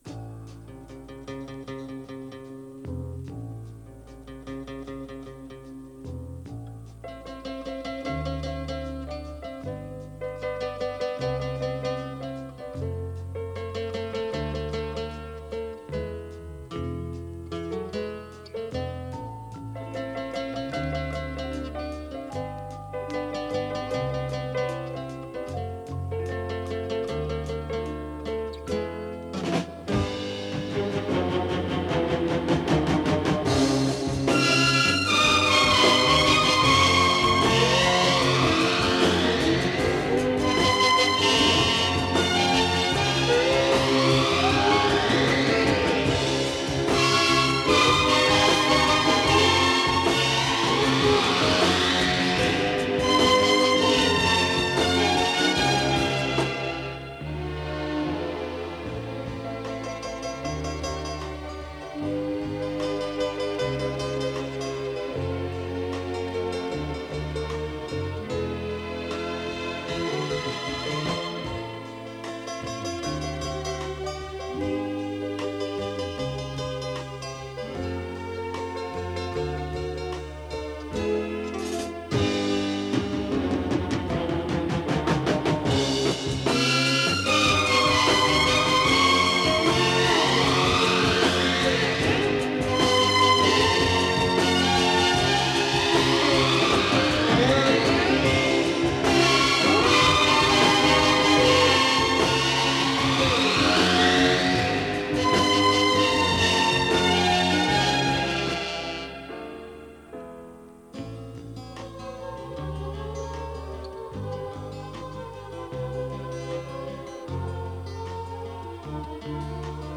Скрипки всегда были главными «певцами» его оркестра.